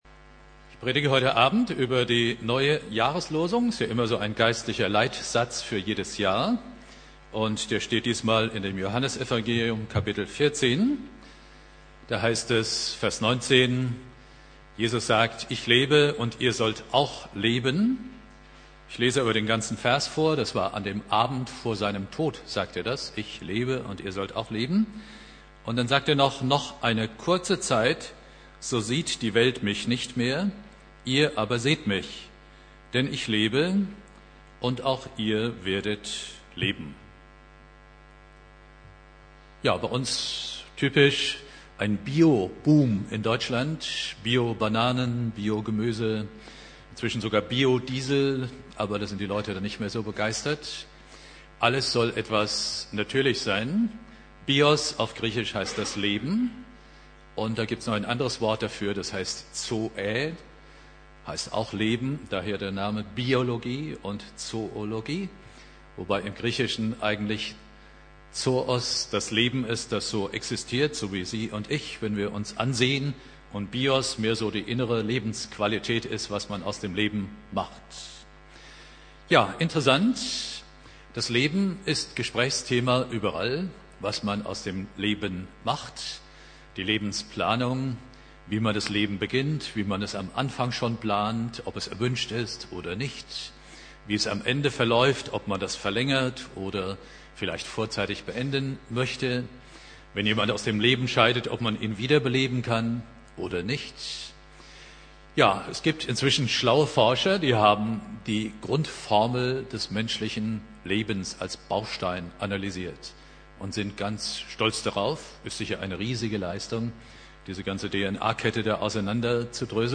Predigt
Neujahr